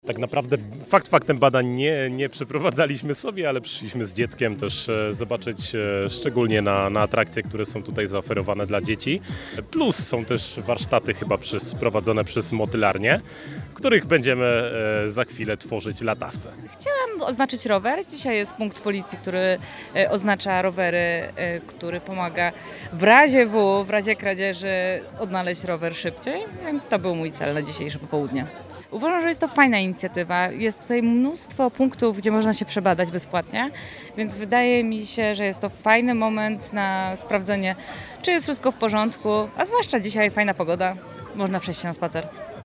Zdrowa Aktywna Długołęka [relacja z wydarzenia]
Jak podkreślali mieszkańcy, była to świetna okazja do profilaktyki: „Jest tu mnóstwo punktów, gdzie można się przebadać bezpłatnie”.